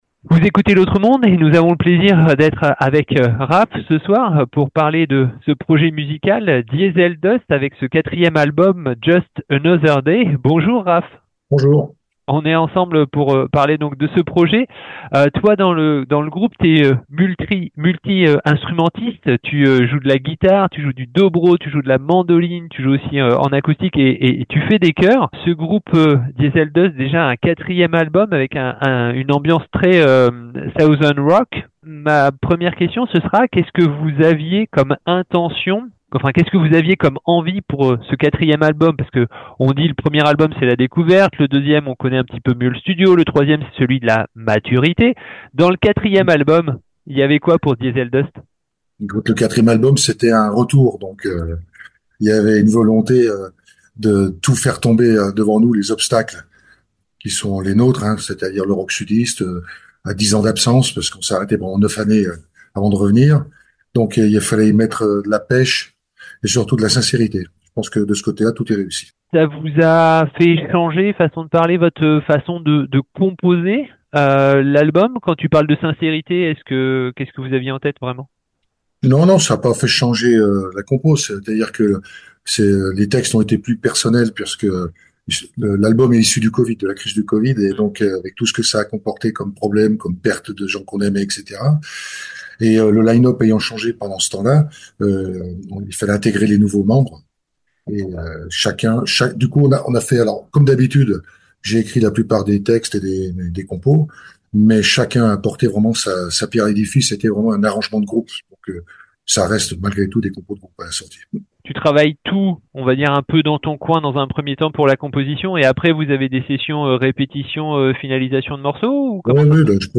Diesel Dust - itw (2024)